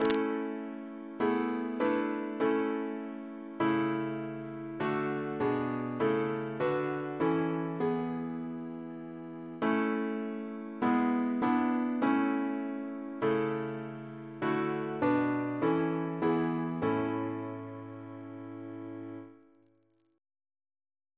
Double chant in A Composer: Thomas Bennett (1779-1848) Reference psalters: PP/SNCB: 191